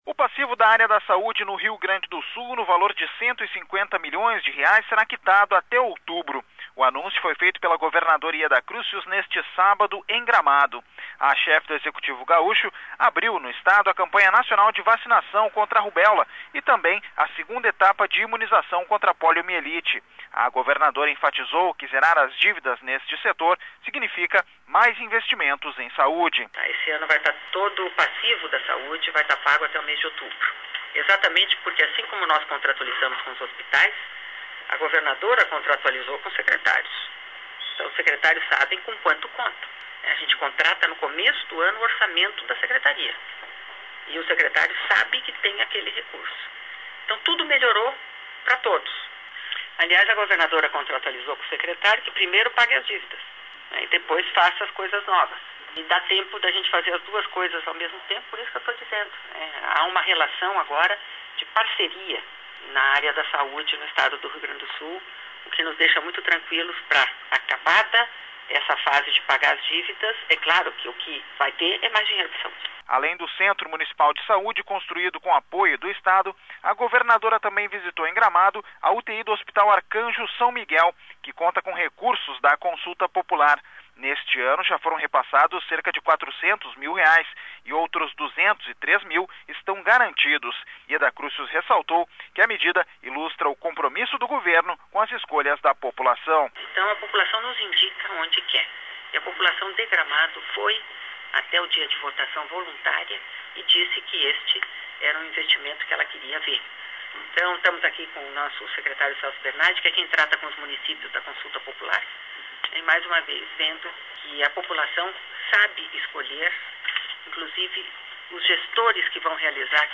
Com a quitação do passivo de R$ 150 milhões, até outubro, o Estado terá mais recursos para investir em saúde pública, disse a governadora em Gramado, onde houve lançamento da campanha nacional de vacinação contra rubéola e da segunda etapa de 2008 da